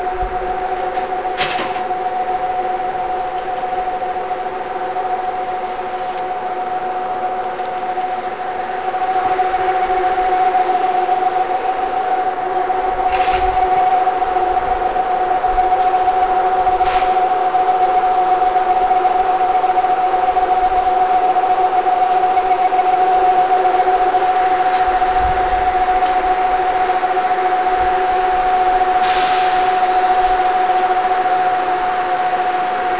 EF63形電気機関車　連結作業音信越本線
軽井沢駅